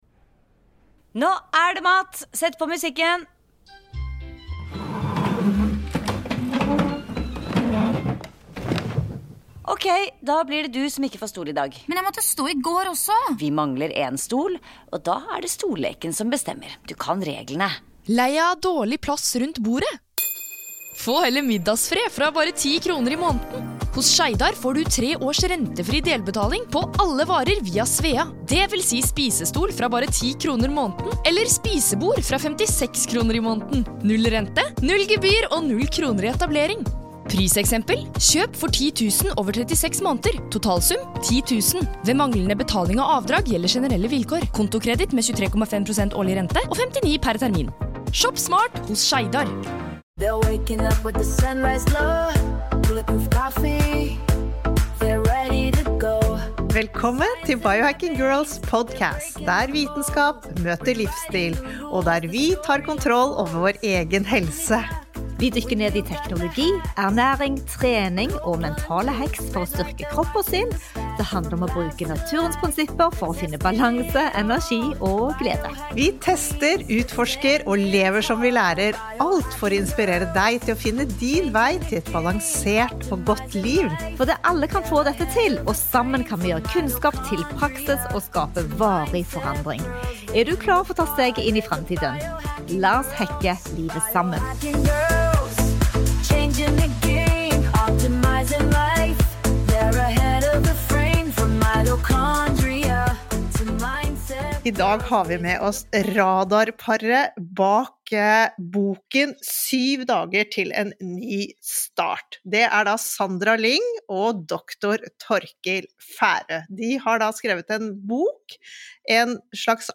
En av stjerne coachene bak denne suksessen Dr. John Demartini er med oss på podkasten og det er et rørende møte fylt med tårer for oss alle tre og sannheter.